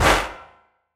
Clap SwaggedOut 5.wav